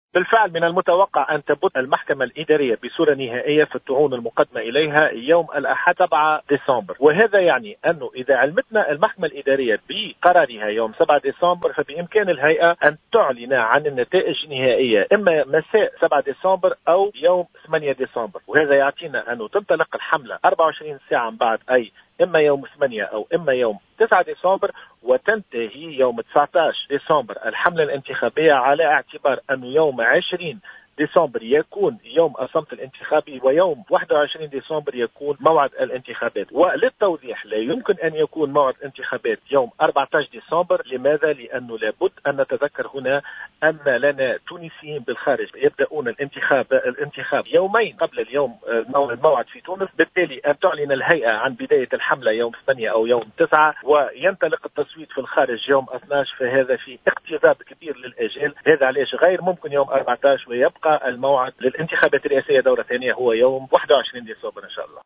قال عضو الهيئة العليا المستقلة للانتخابات نبيل بافون في تصريح ل"جوهرة أف أم" إن يوم الاقتراع في الدور الثاني من الانتخابات الرئاسية سيكون يوم 21 ديسمبر الحالي.